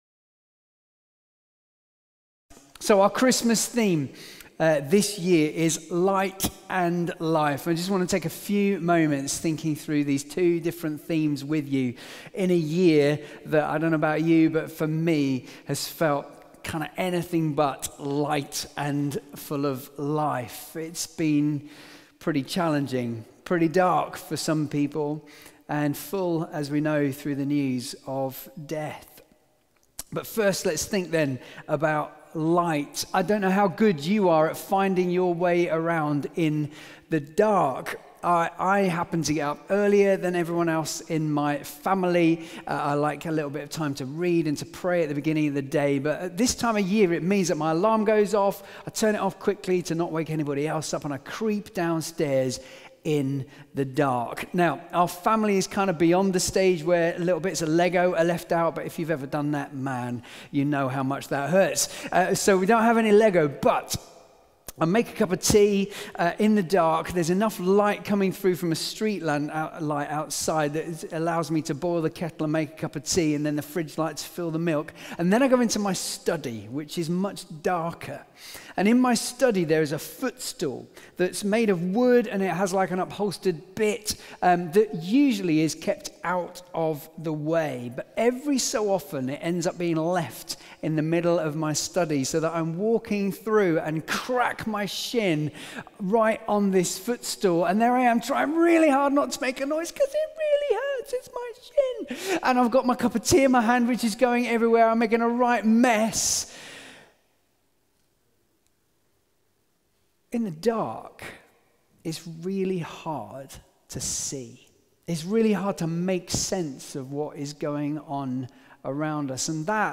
Carols by Candlelight - Sunday 6th December 2020